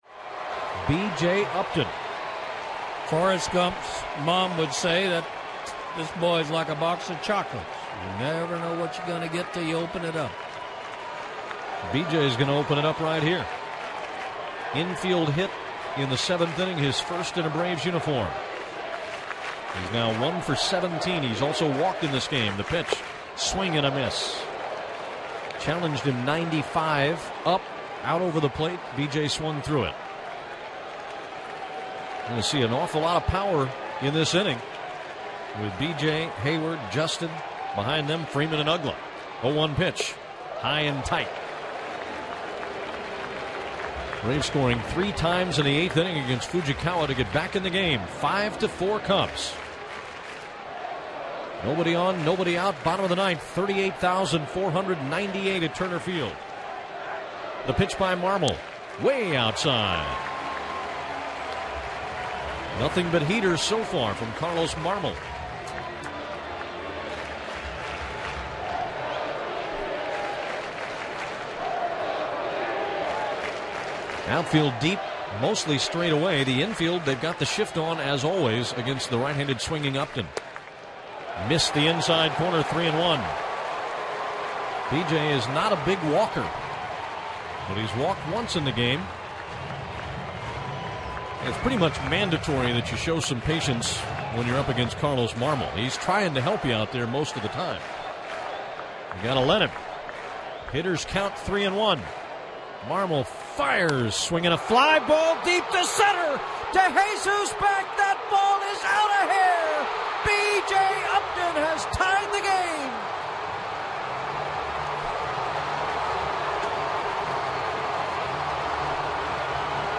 April 6, 2013 - BJ Upton's first home run as a Brave couldn't have come at a better time. Jim Powell and Don Sutton with the call.